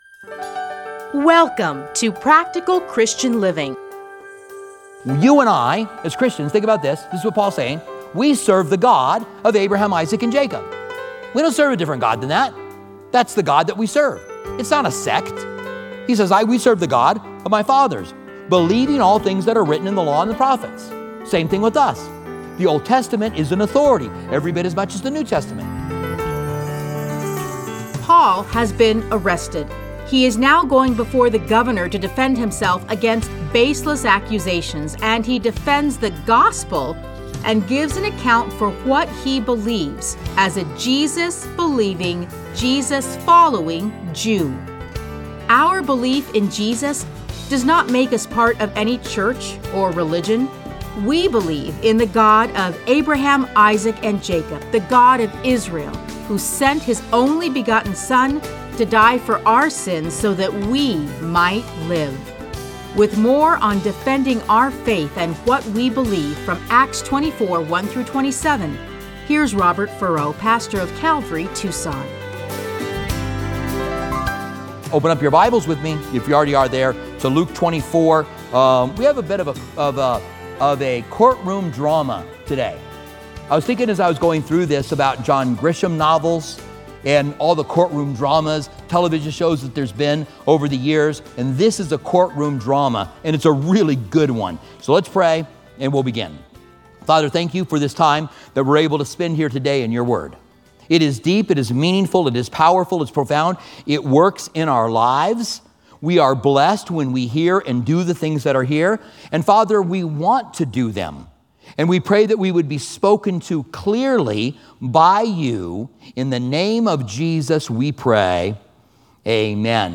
Listen to a teaching from Acts 24:1-27.